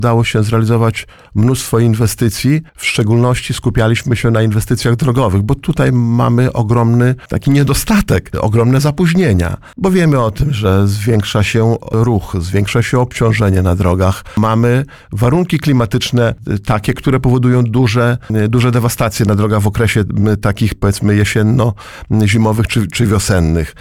Był to niesamowicie pracowity czas – mówił na naszej antenie starosta powiatu łomżyńskiego, Lech Szabłowski o mijającej 5-letniej kadencji.